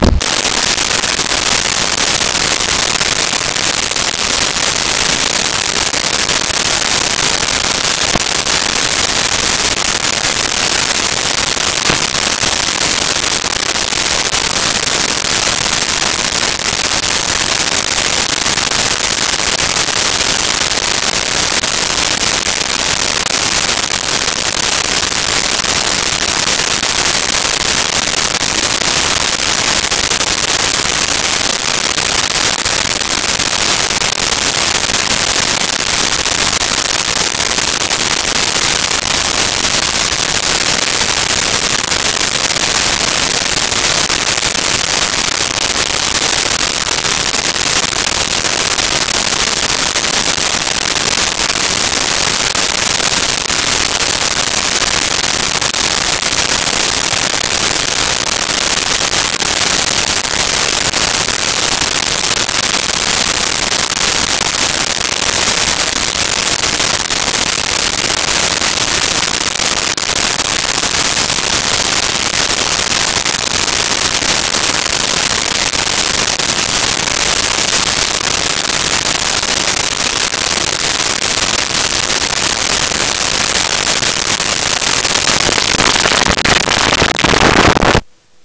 Tv Static
TV Static.wav